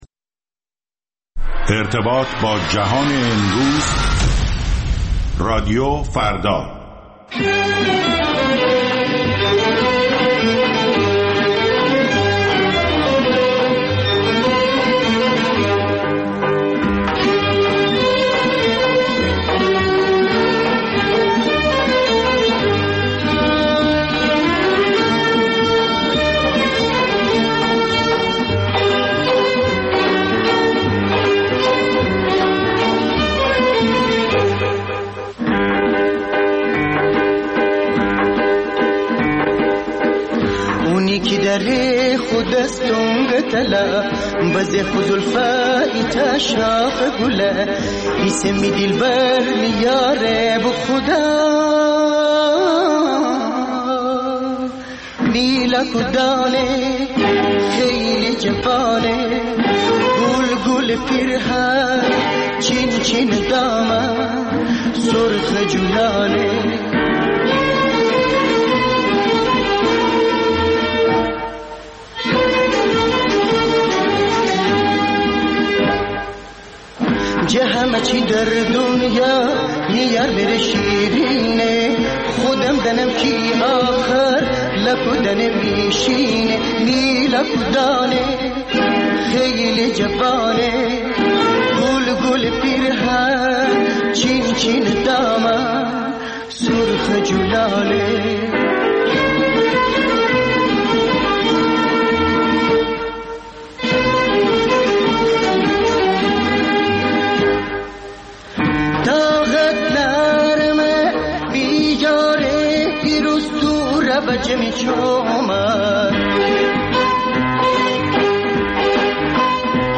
ویژه برنامه موسیقی محلی ایران